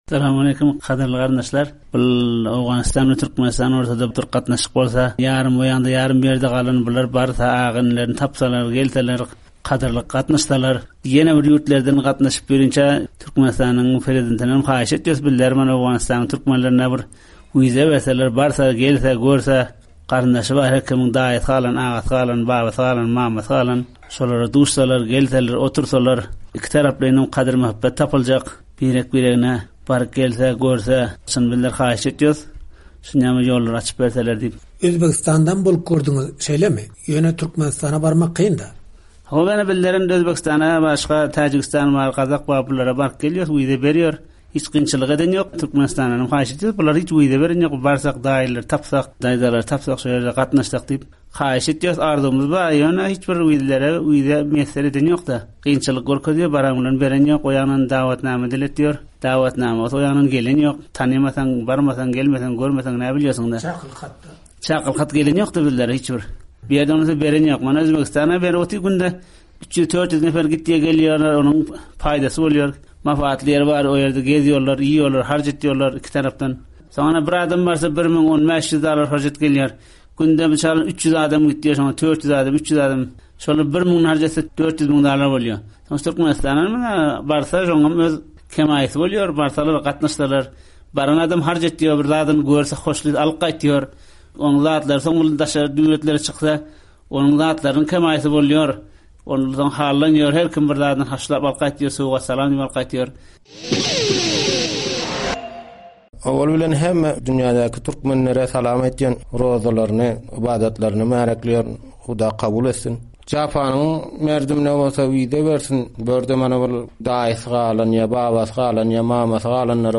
Bu söhbetlerden bir bölegi ünsüňize hödürleýäris we gozgalan mesele boýunça öz pikirleriňizi paýlaşmaga çagyrýarys.